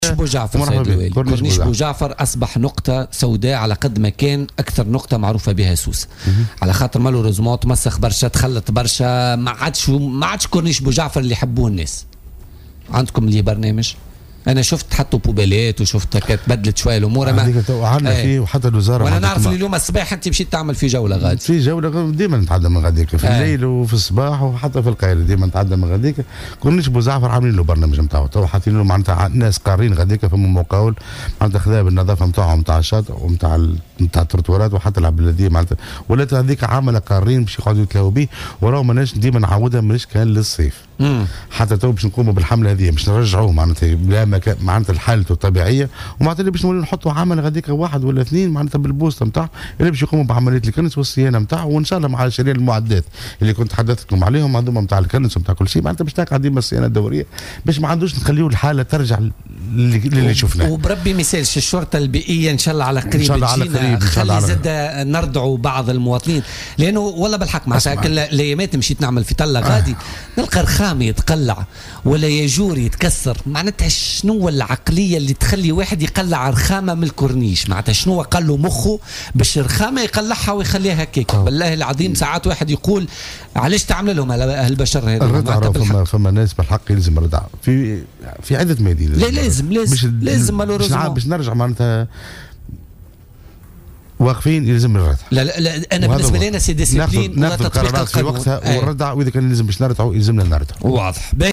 وقال ضيف "بوليتيكا" إنه تم تخصيص عملة قارين للعناية بالكورنيش بشكل يومي، مضيفا أن الردع ضروري ضد مواطنين يتعمدون تخريب هذا المكان الذي تتميز به سوسة.